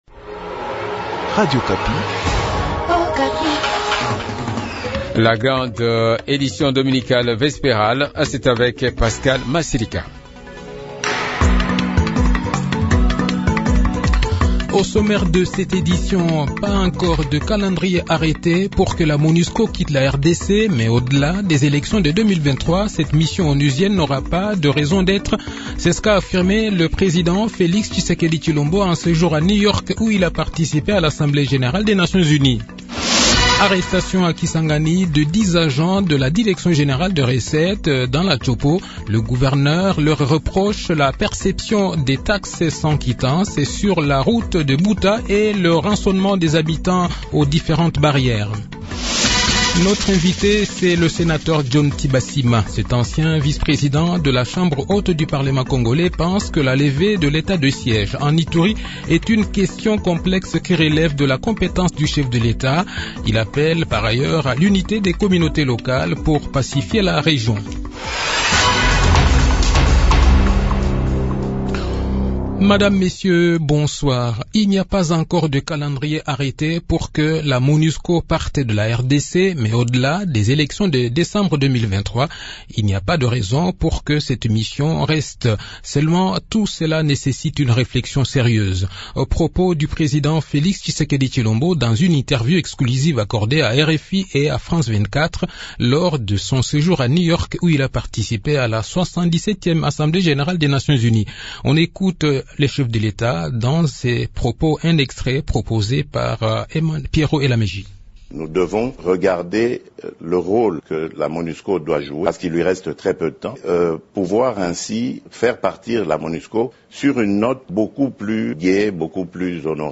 Le journal de 18 h, 25 septembre 2022